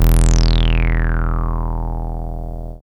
78.04 BASS.wav